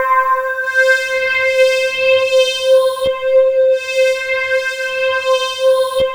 Index of /90_sSampleCDs/USB Soundscan vol.13 - Ethereal Atmosphere [AKAI] 1CD/Partition E/11-QUARTZ